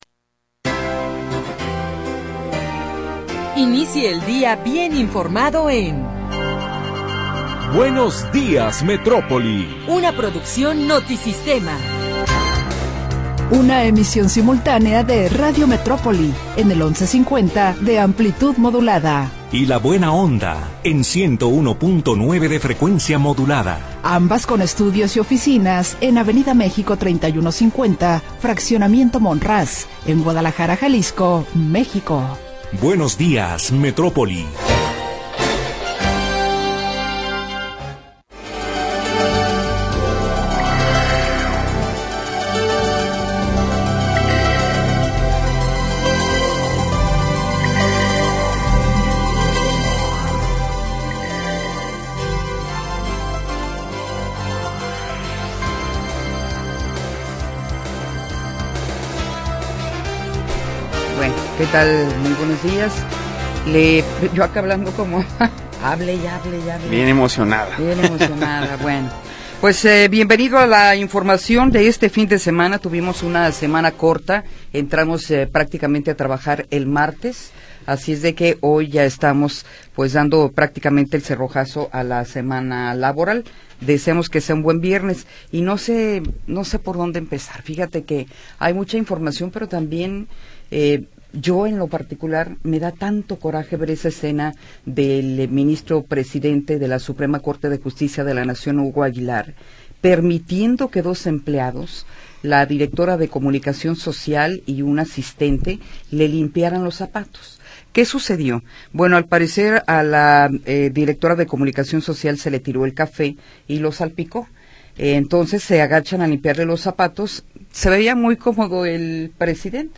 Primera hora del programa transmitido el 6 de Febrero de 2026.